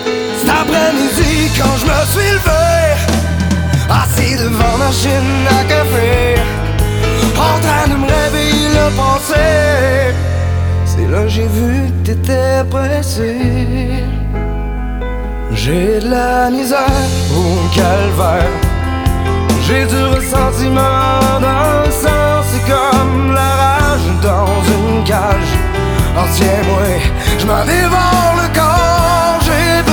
• Musique francophone